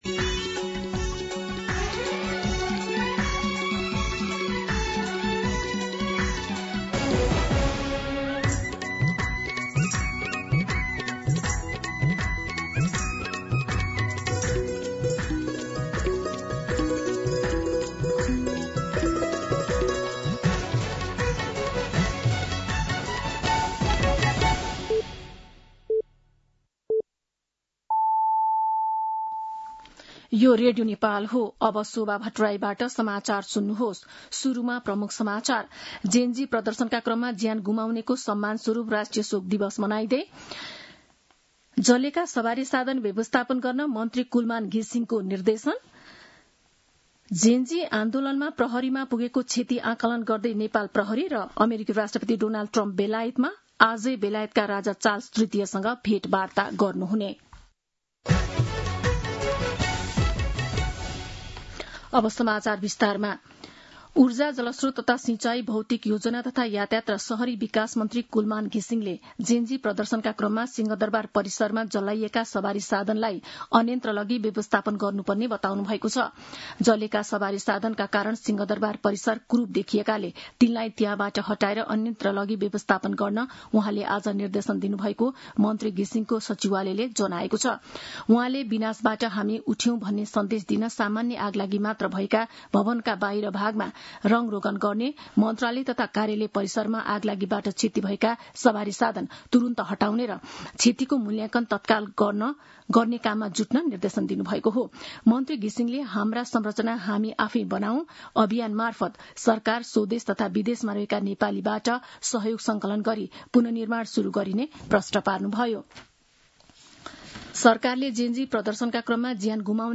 दिउँसो ३ बजेको नेपाली समाचार : १ असोज , २०८२
3-pm-Nepali-News-1.mp3